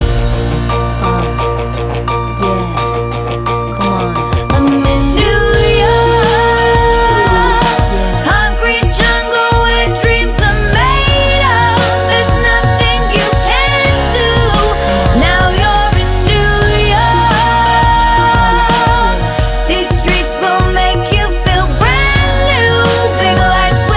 [For Solo Rapper] R'n'B / Hip Hop 4:35 Buy £1.50